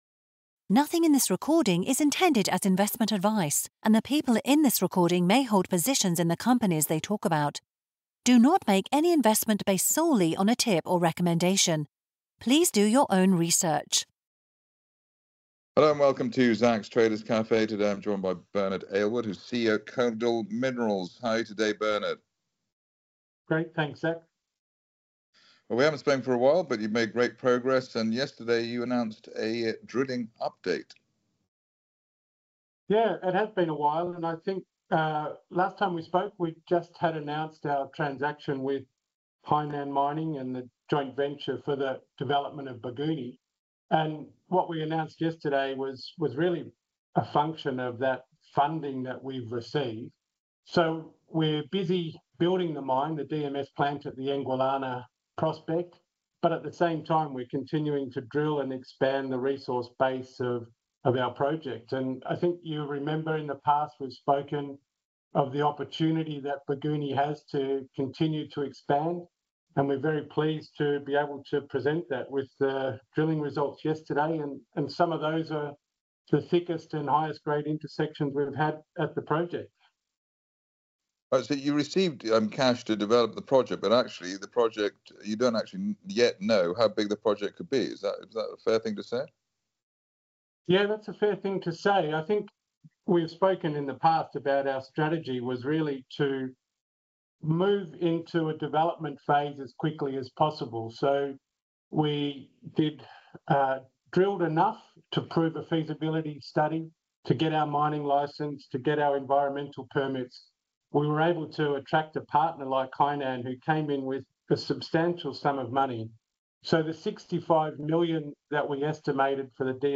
Key points from the interview